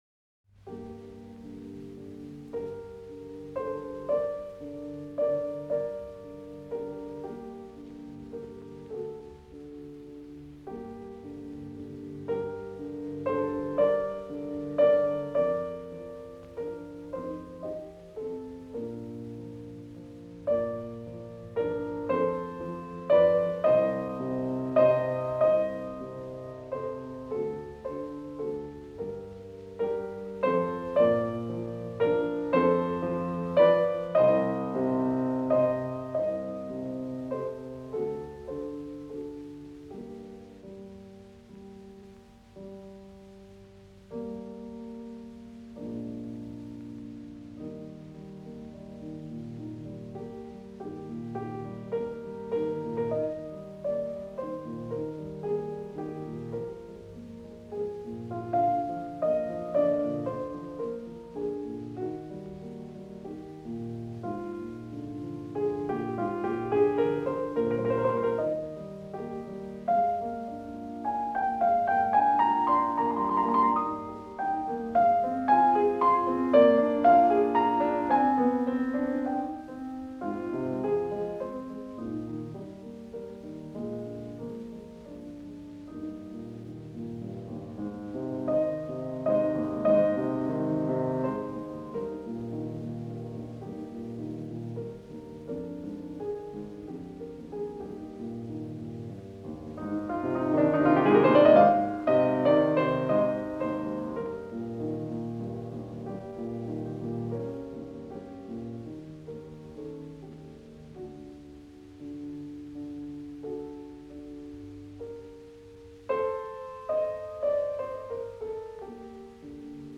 아르투르 슈나벨의 연주; 뮤즈오픈
사단조, 9/8 박자, 세도막 형식이다.
중간부에서는 아르페지오 반주 위에 다음 악보 4가 연주된다.[7]
코다에서는 중간부의 반주 음형 위에 악보 3의 주제가 다시 연주되며 조용히 마무리된다.